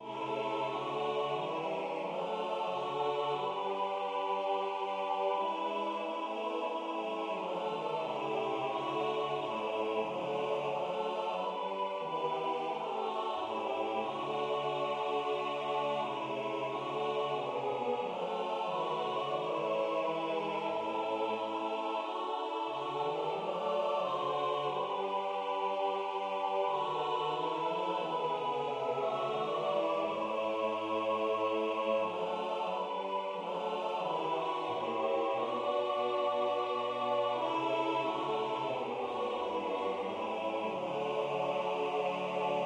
The following alto aria, calling the world a "snare and false pretense",[1] is dominated again by the flute.[10] The arias for tenor and soprano are set in dance rhythms, Pastorale and Bourrée, describing the "world" rather than disgust with it.[11] The cantata is concluded by the last two stanzas of the chorale in a four-part setting.[4][12]